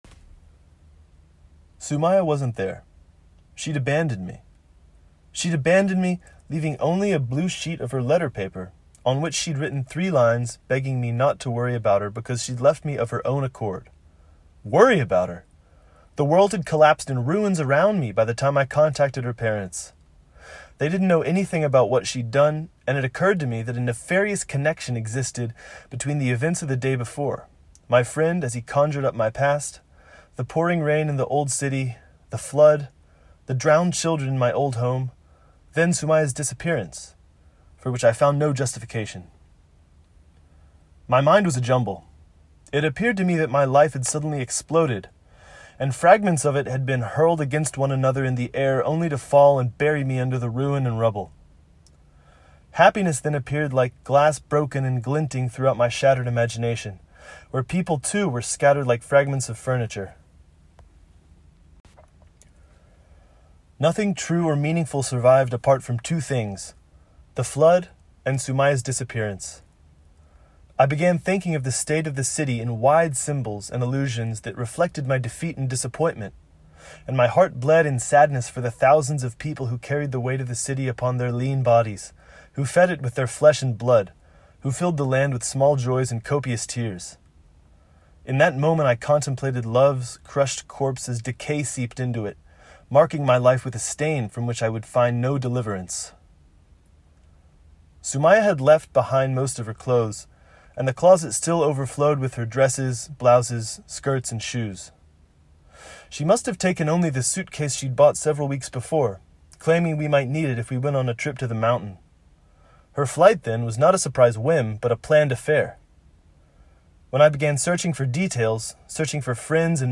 Cry-in-a-Long-Night-English.mp3